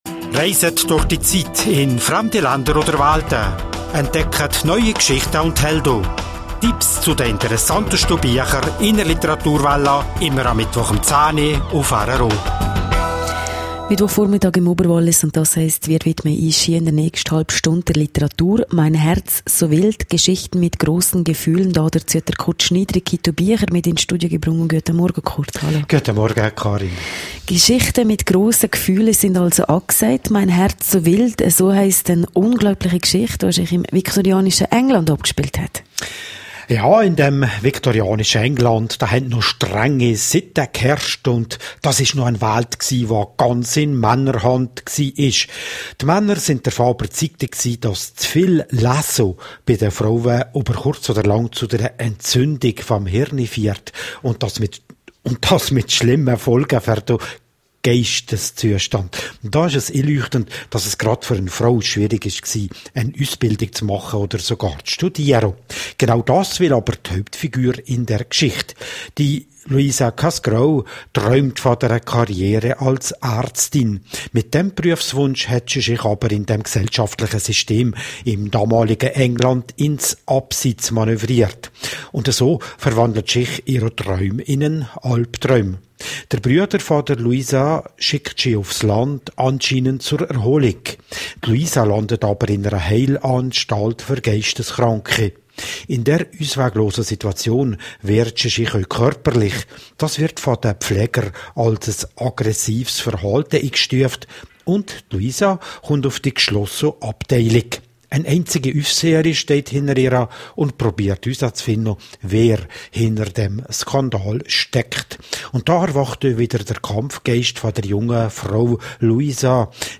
Literaturwälla